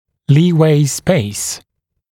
[‘liːweɪ speɪs][‘ли:уэй спэйс]